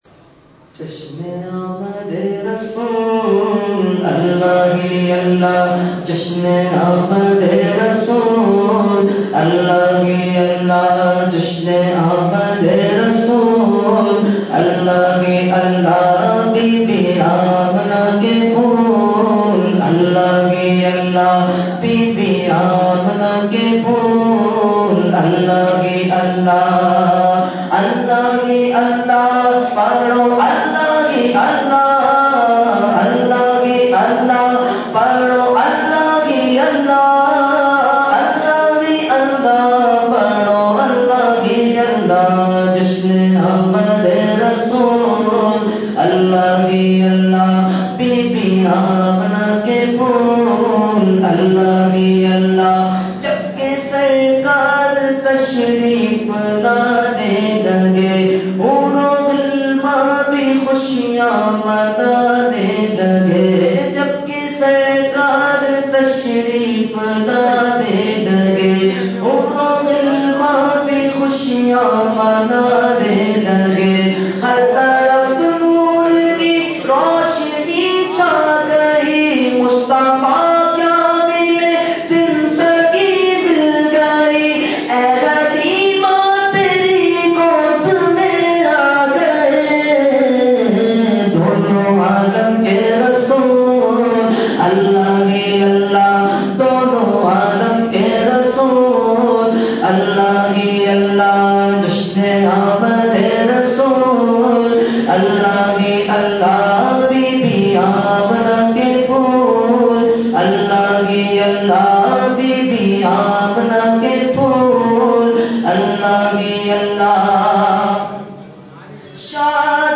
Category : Naat | Language : UrduEvent : Mehfil Milad Akhund Masjid Kharader 10 January 2014